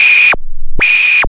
1705L SYNSEQ.wav